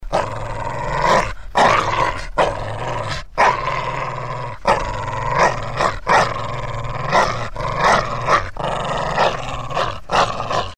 Звук рычания собаки